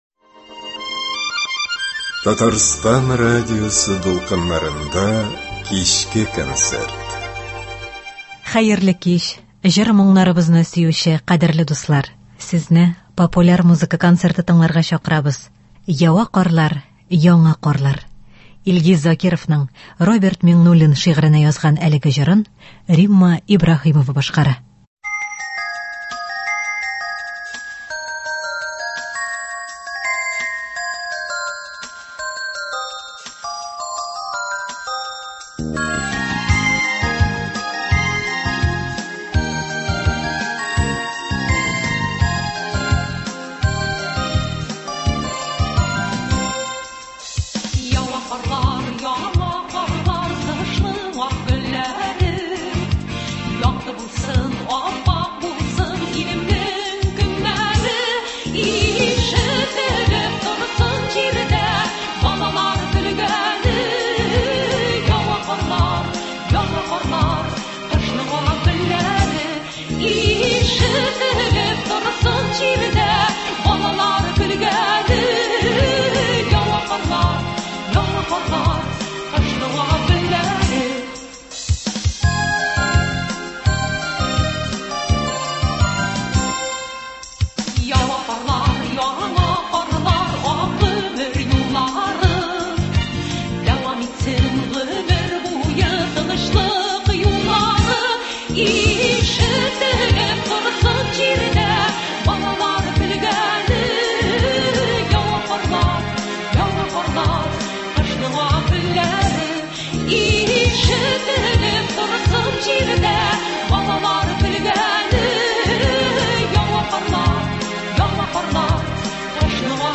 Популяр музыка концерты.